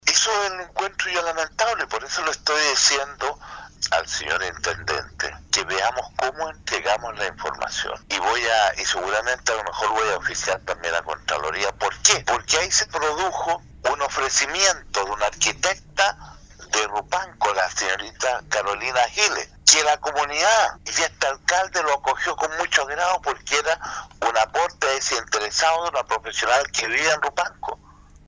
CUÑA-DON-CARLOS-ESCUELA-2-.mp3